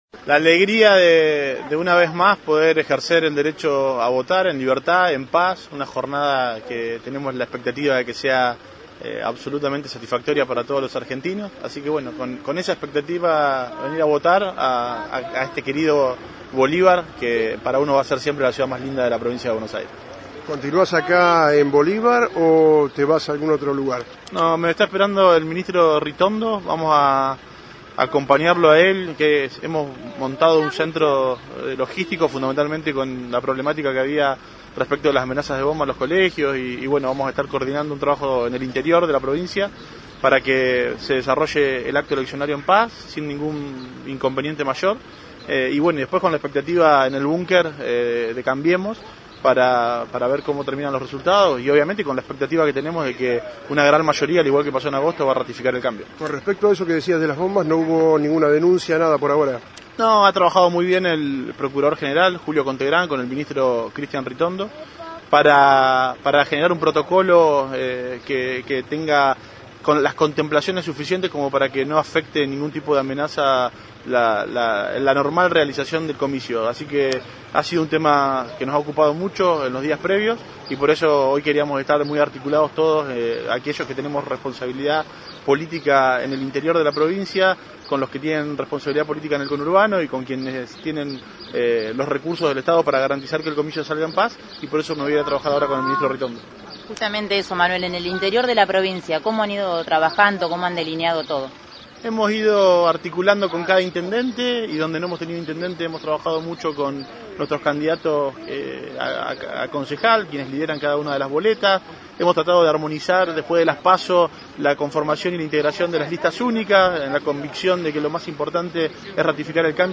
LA PALABRA DEL PRESIDENTE DE LA CAMARA DE DIPUTADOS DE LA PROVINCIA DE BUENOS AIRES